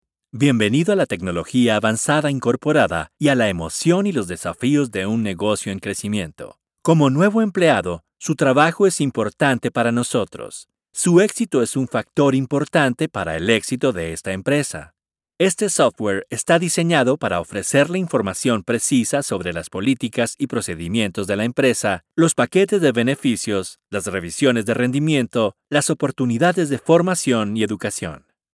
Smooth, Energetic, Professional
Corporate